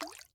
drip_water_cauldron7.ogg